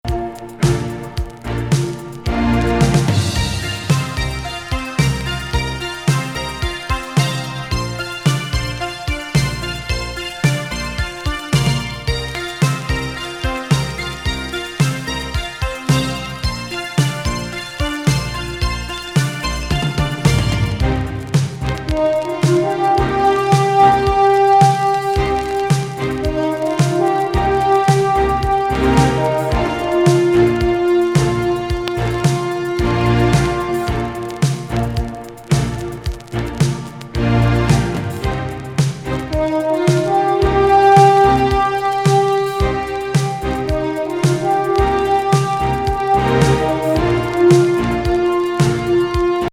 室内楽的アンビエンス＋エスノ・ニューエイジ作!フワフワトロトロ～